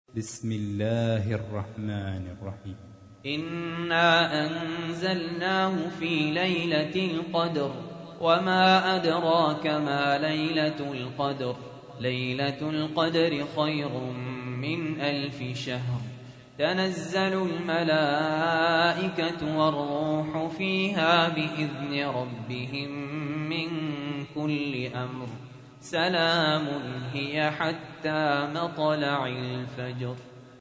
تحميل : 97. سورة القدر / القارئ سهل ياسين / القرآن الكريم / موقع يا حسين